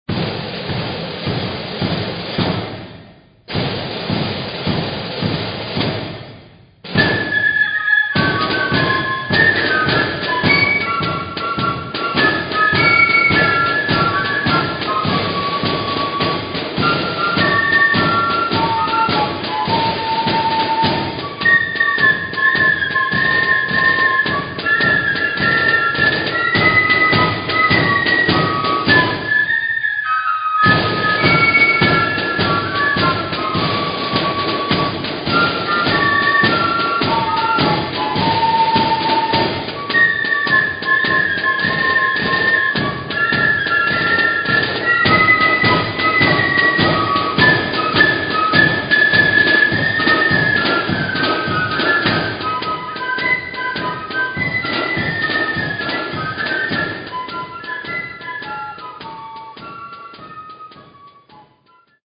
melody flute bands